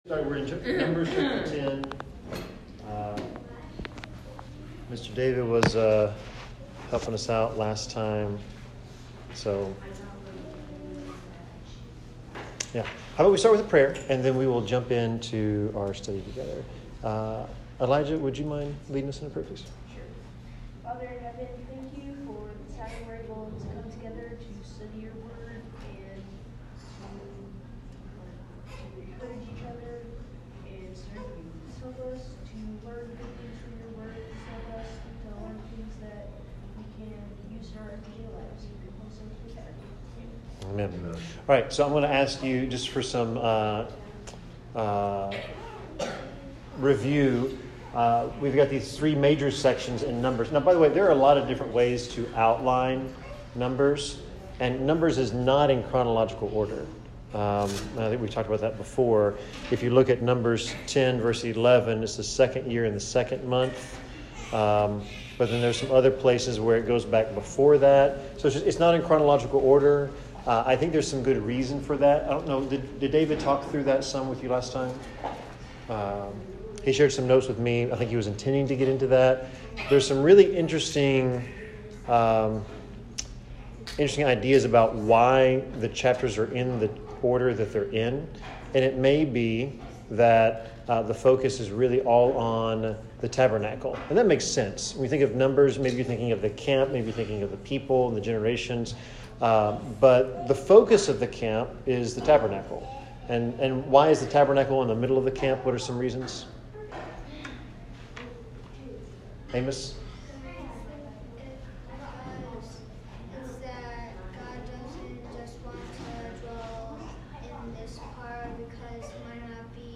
Numbers 10-11 Service Type: Bible Class « Acts 1 The Power of the Kingdom Acts 2 How Shall We Call Upon the Lord?